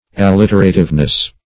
Search Result for " alliterativeness" : The Collaborative International Dictionary of English v.0.48: Alliterative \Al*lit"er*a*tive\ (?; 277), a. Pertaining to, or characterized by, alliteration; as, alliterative poetry.